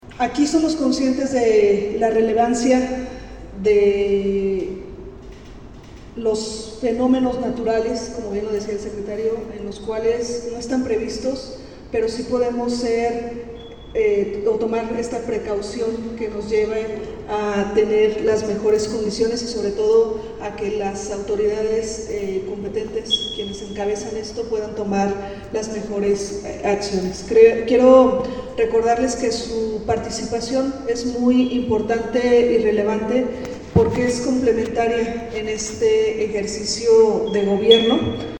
AudioBoletines
Adriana Elizarrarás, secretaria de ayuntamiento